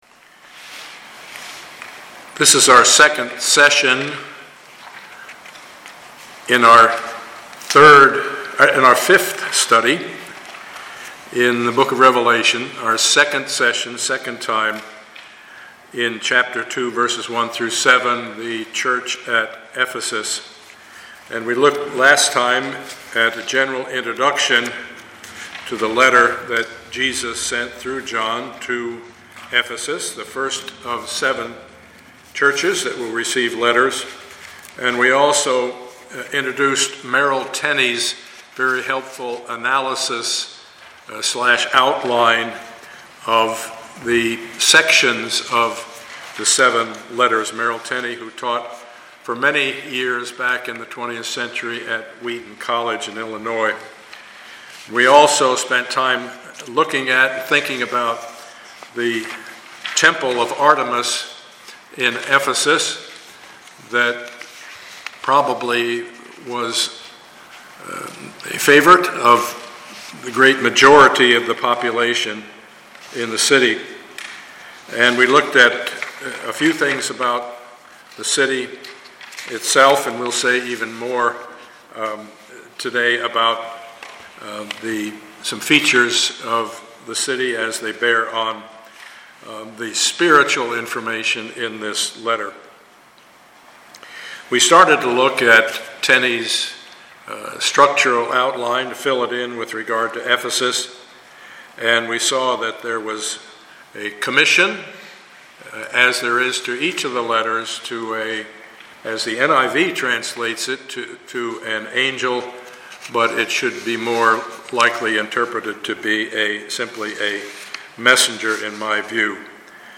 Passage: Revelation 2:1-7 Service Type: Sunday morning